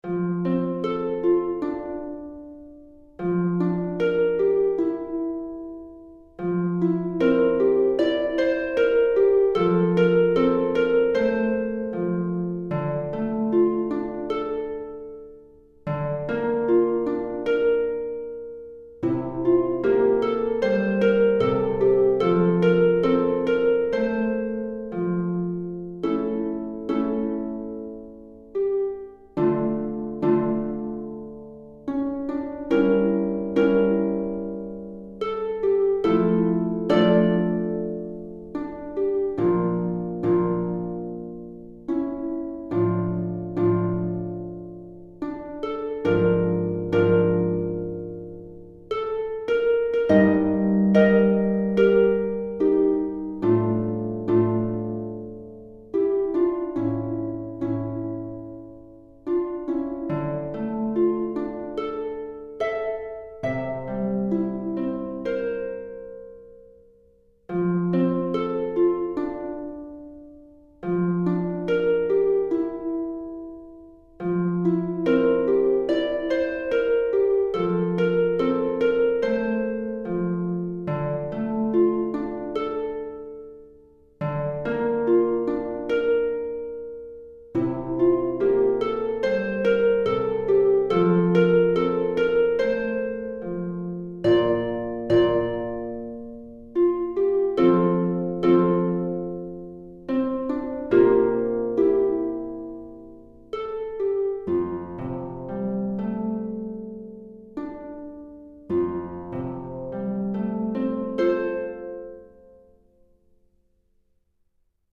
Pour harpe (ou harpe celtique) DEGRE CYCLE 1
Harpe solo